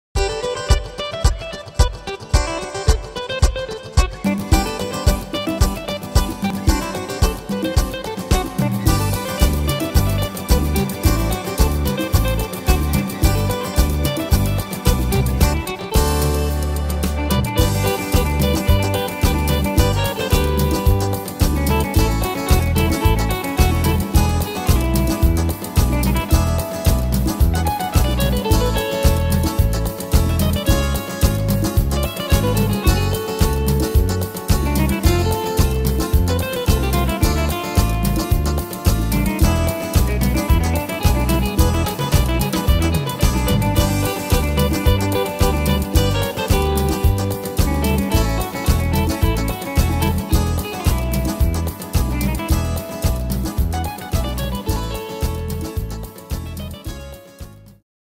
Tempo: 110 / Tonart: G-Dur